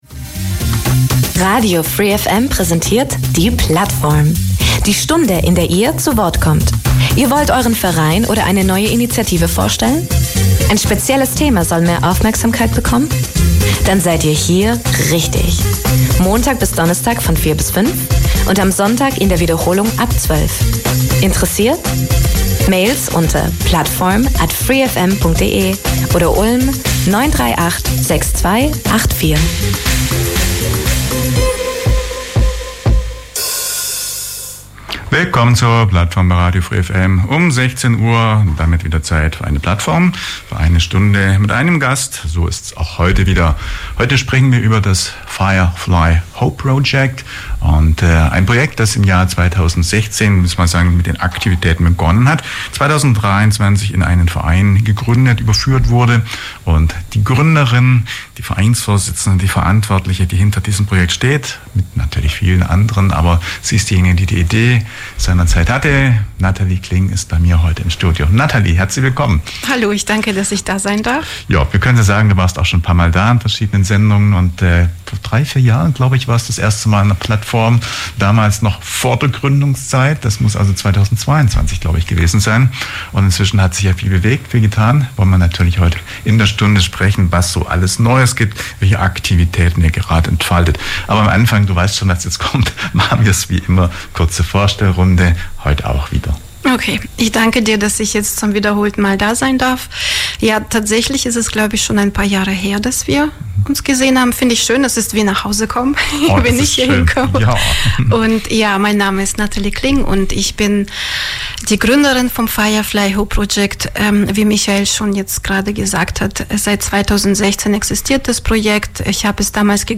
Let´s be a firefly - let´s be hope. Was das bedeutet, erzählt uns ein internationales Team vom Firefly Hope Project e.V. in der heutigen Ausgabe der Plattform.